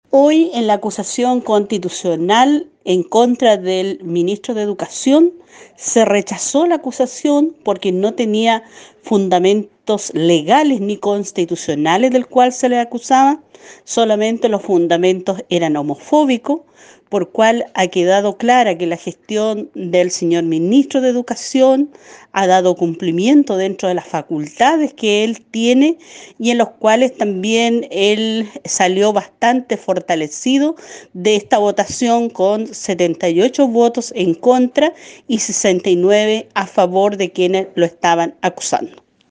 Una de las parlamentarias que votó en contra de esta acusación fue la representante del Distrito 25, Emilia Nuyado Ancapichún, quien señaló que esto respondía a una maniobra política más que a una fiscalización por parte del órgano legislador.